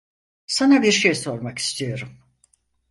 Uitgesproken als (IPA)
[soɾˈmak]